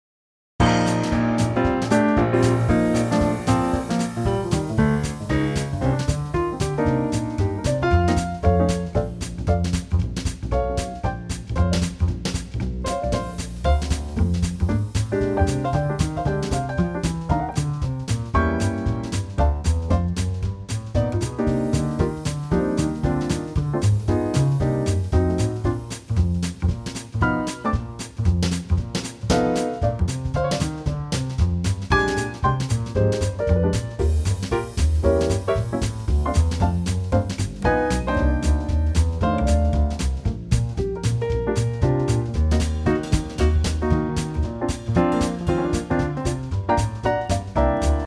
Unique Backing Tracks
key - Bb - vocal range - F to Bb
Bright 2025 arrangement